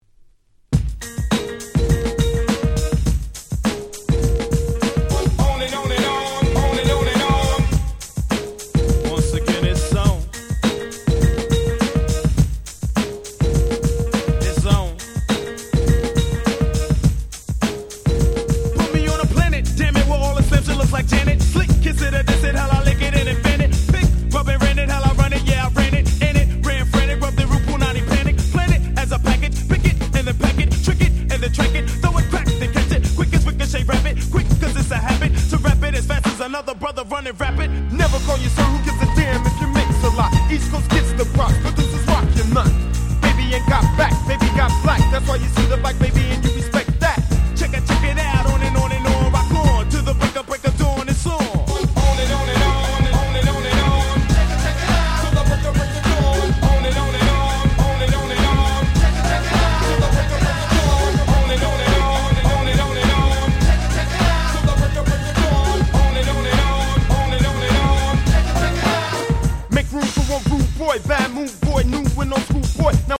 90's Boom Bap ブーンバップ